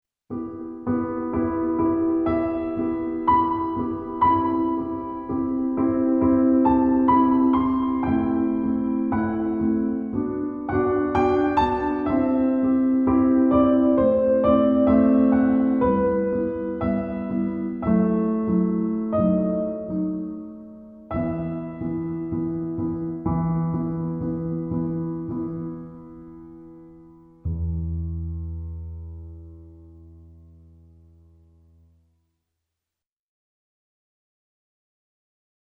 The CD insert has the message "All of the multi-Patch examples and songs were one realtime Performance on an XV-5080. No audio overdubbing was used to exceed the realtime capability of the unit."
Concert Hall patch demo
03-Concert-Hall.mp3